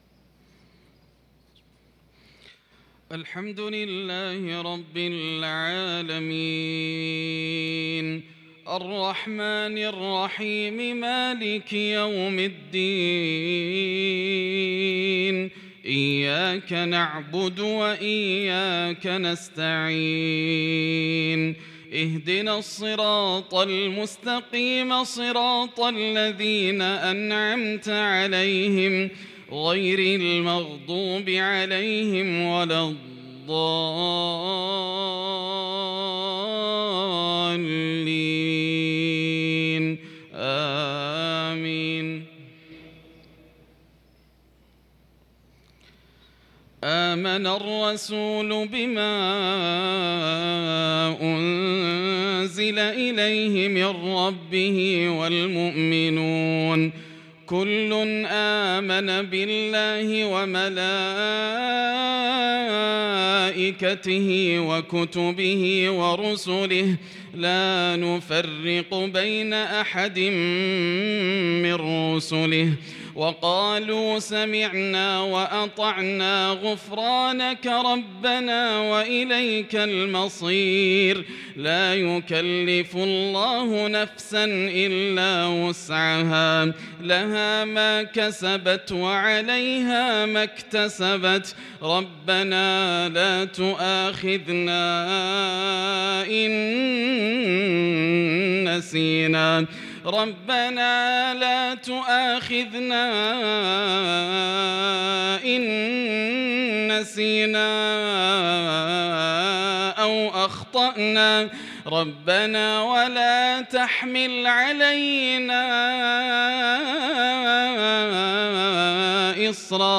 صلاة المغرب للقارئ ياسر الدوسري 25 محرم 1443 هـ